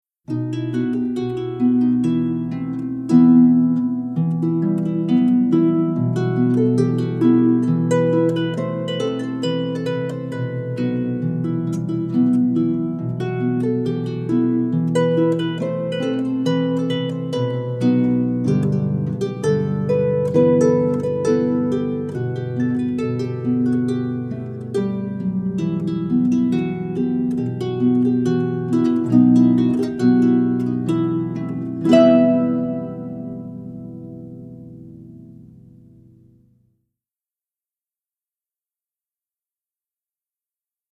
is for solo lever or pedal harp